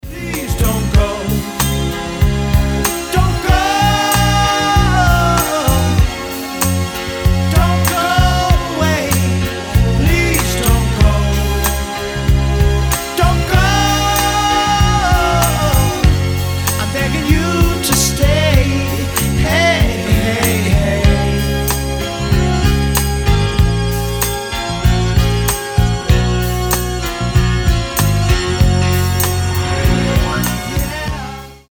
• Качество: 320, Stereo
70-е
соул